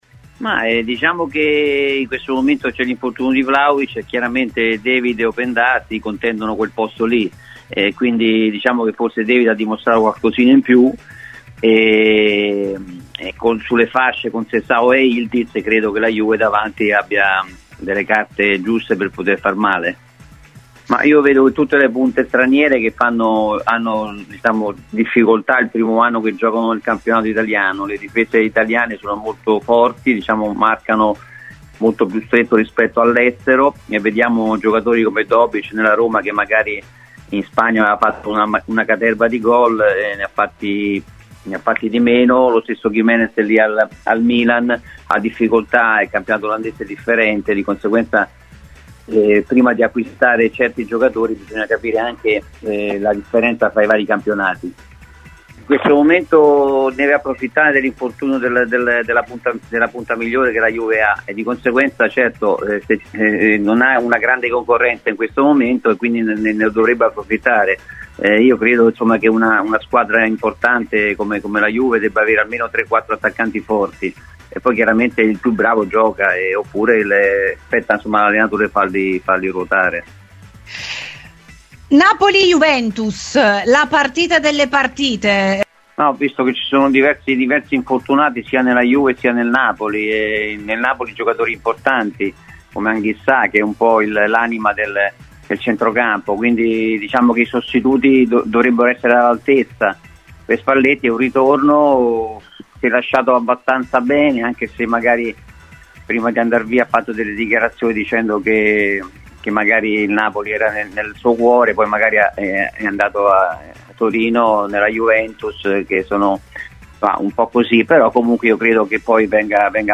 Alla Juventus tra il 1985 e il 1987, Lionello Manfredonia è stato oggi ospite di “RBN Cafè” su Radio Bianconera ed ha commentato il momento della squadra di Spalletti, che nei prossimi mesi dovrà fare a meno di Dusan Vlahovic: “Con il numero 9 fuori dai giochi saranno David e Openda a contendersi il posto. Ad oggi il canadese ha dimostrato qualcosina in più, e in generale con Conceiçao e Yildiz sulle fasce, la Juventus ha ottime potenzialità in avanti”.